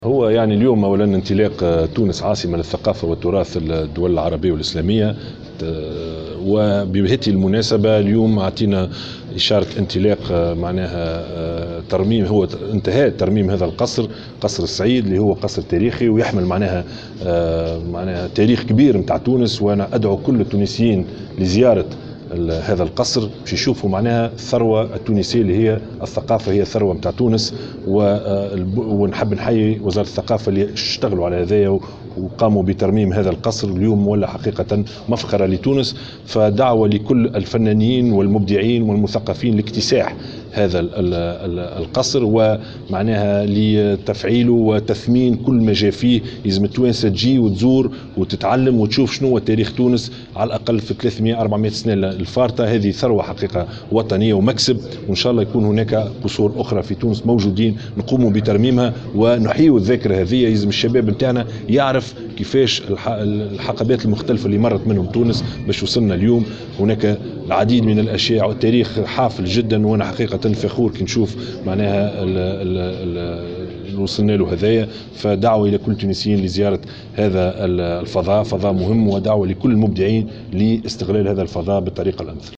اعتبر رئيس الحكومة يوسف الشاهد قصر السعيد "قصر الآداب والفنون" بباردو الذي قام بتدشينه اليوم الخميس 21 مارس 2019، مكسبا وطنيا هاما.